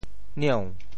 茑（蔦） 部首拼音 部首 艹 总笔划 14 部外笔划 11 普通话 niǎo 潮州发音 潮州 zieu2 文 nieu2 文 中文解释 茑 <名> 寄生木 [birdvine and viscum]。
nieu2.mp3